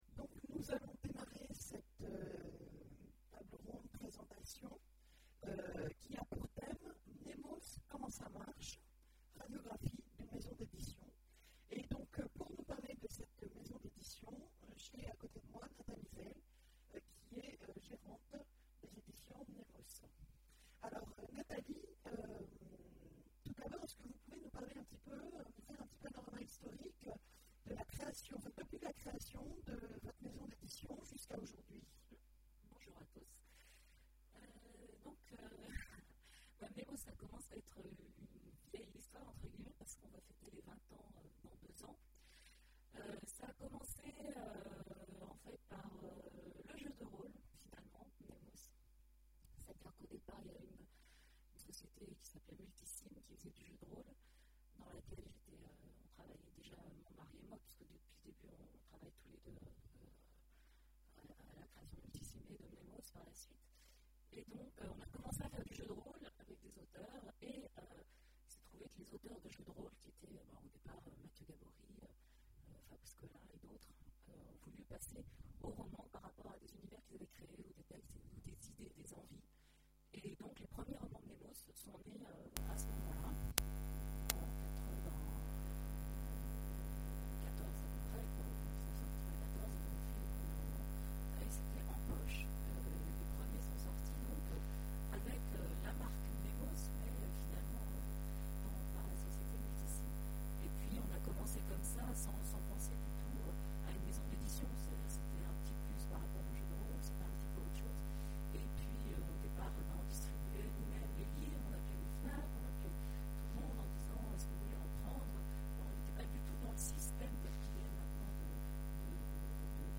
Imaginales 2014 : Conférence Mnémos comment ça marche?